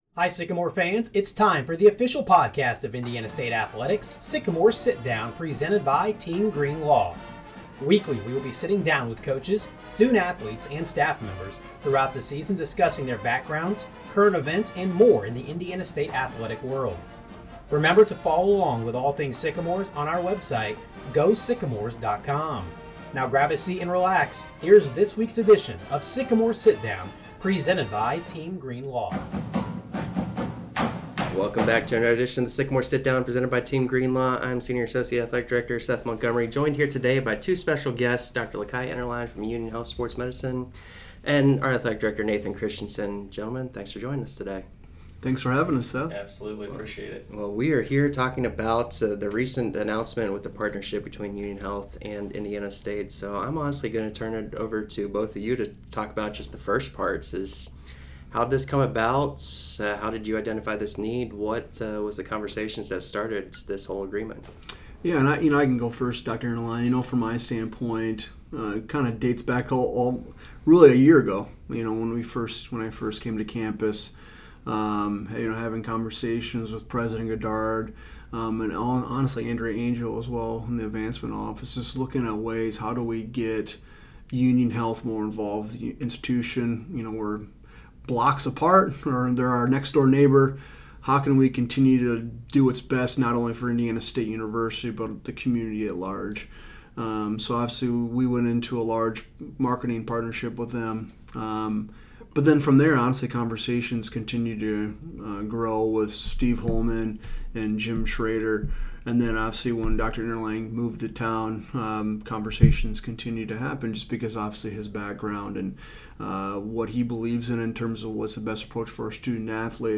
The podcast will feature Sycamore administrators, coaches, athletes, alumni, and more as we sit down with the Sycamores